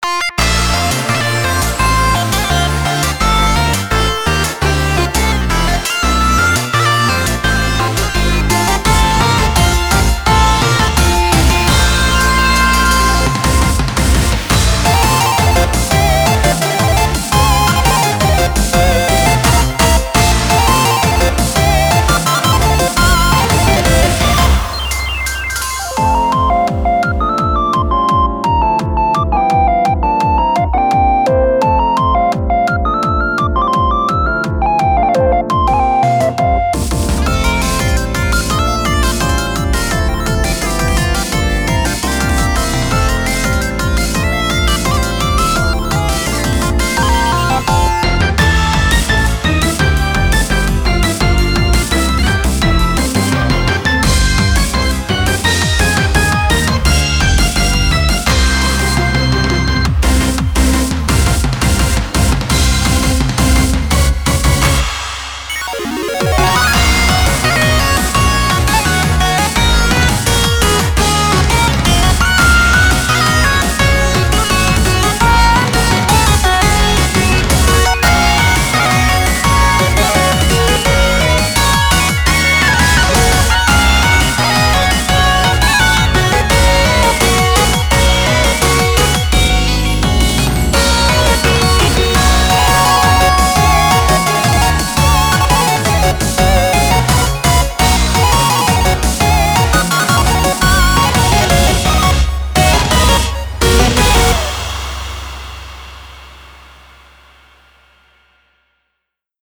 BPM85-170
MP3 QualityMusic Cut